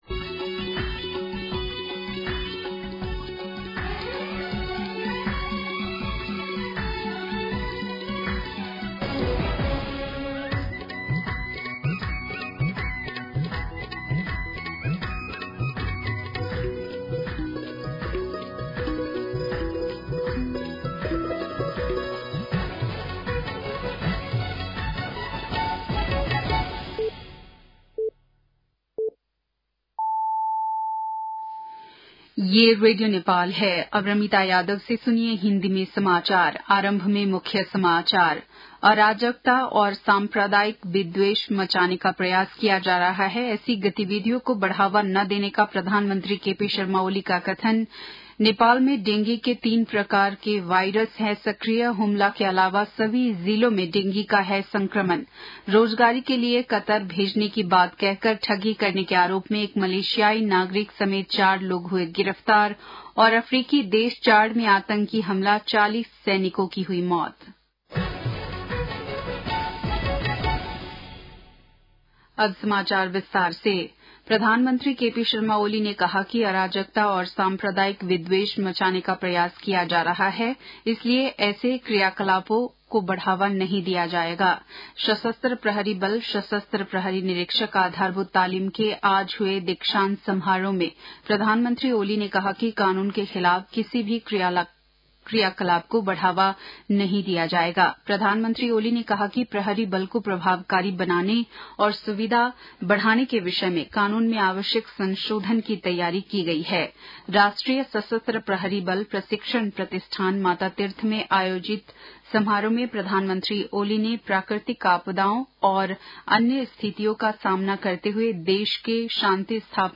बेलुकी १० बजेको हिन्दी समाचार : १४ कार्तिक , २०८१
10-PM-Hindi-News-7-13.mp3